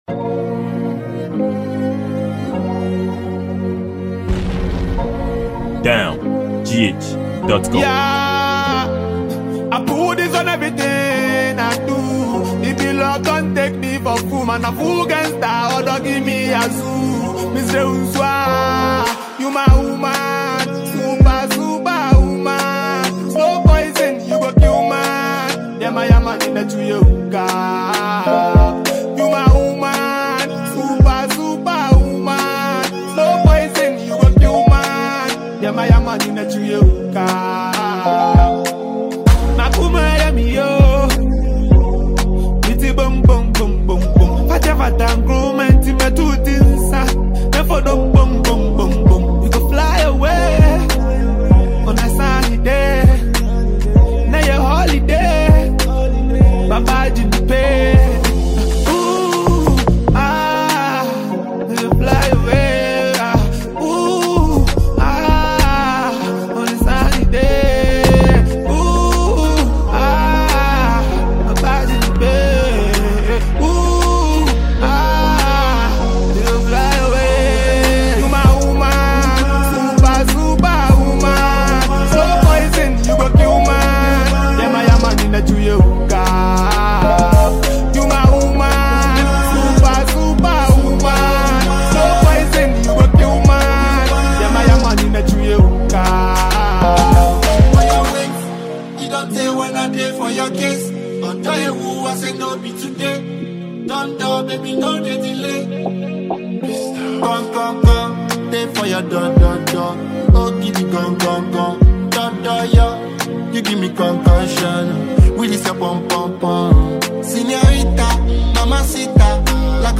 Ghana Music
Ghanaian rapper musician